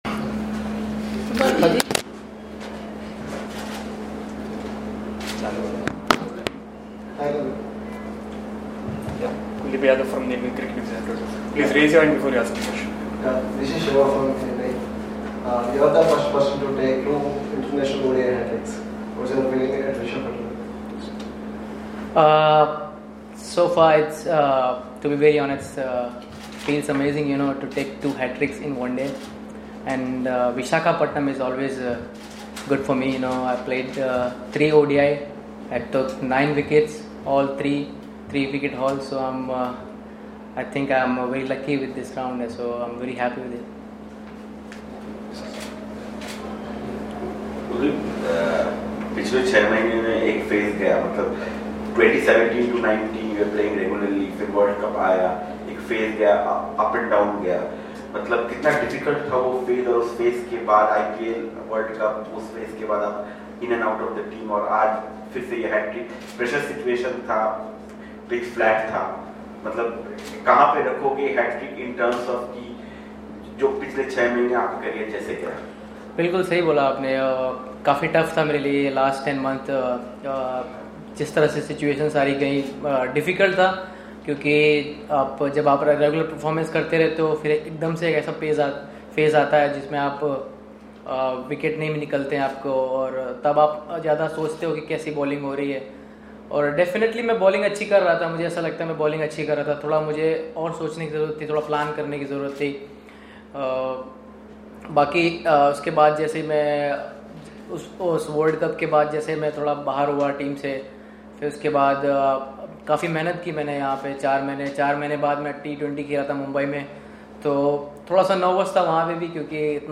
Kuldeep Yadav spoke to the media at Dr. Y.S. Rajasekhara Reddy ACA-VDCA Cricket Stadium in Visakhapatnam after the 2nd Paytm ODI against West Indies.